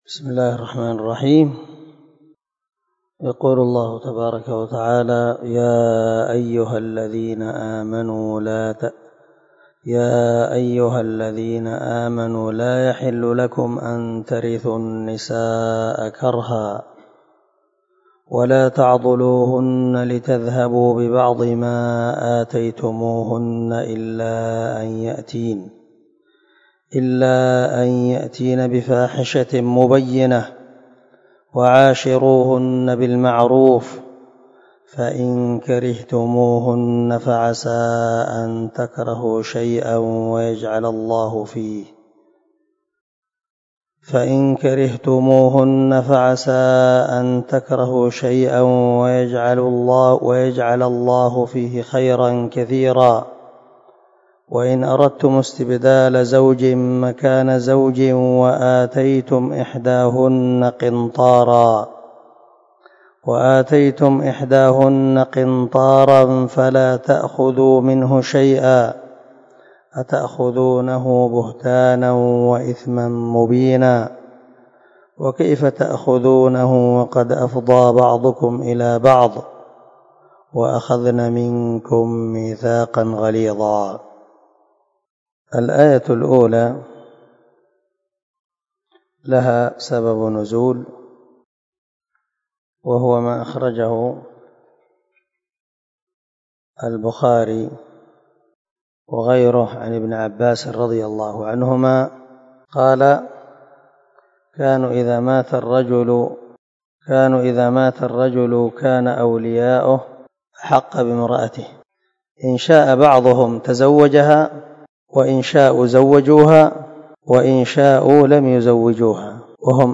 250الدرس 18 تفسير آية ( 19 – 21 ) من سورة النساء من تفسير القران الكريم مع قراءة لتفسير السعدي
دار الحديث- المَحاوِلة- الصبيحة.